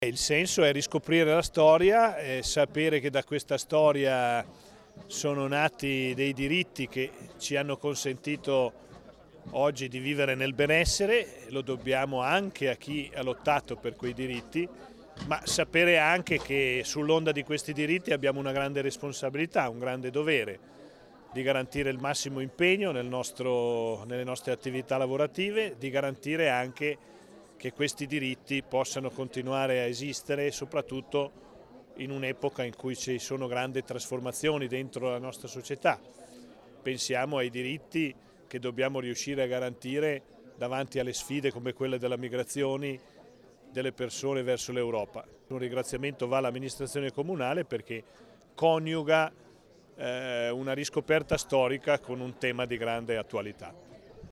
Oggi a Lavis la cerimonia con il presidente Ugo Rossi e l'assessora Sara Ferrari
Scarica il file intervista Rossi 30 aprile_MP3 256K.mp3